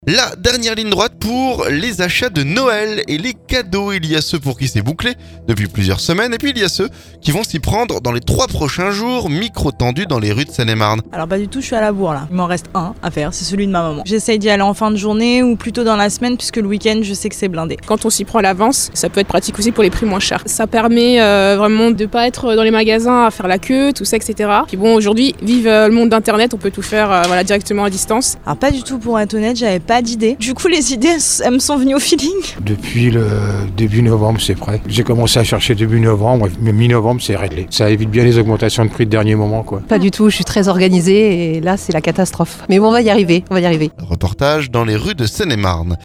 Micro tendu dans les rues de Seine-et-Marne.